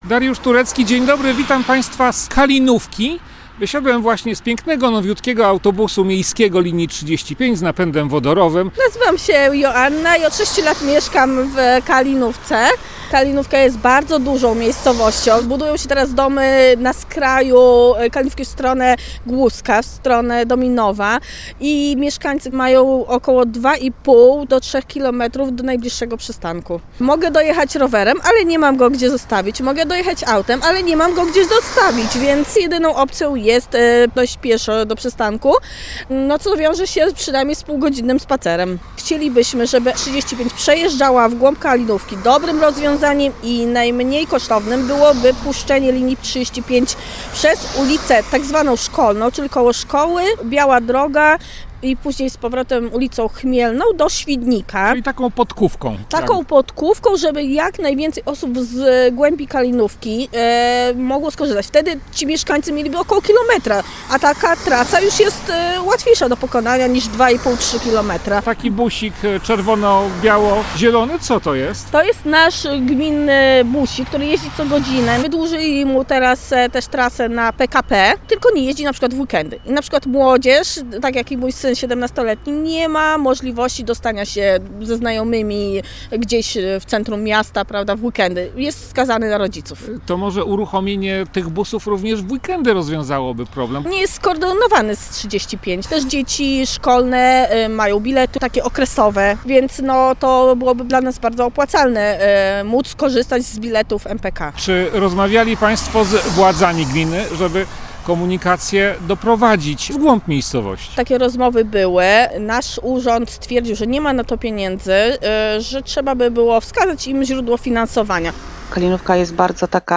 Czy uwagi mieszkanki podziela sołtys Kalinówki Renata Ryś?